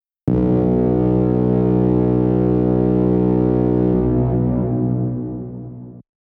Horn in B.wav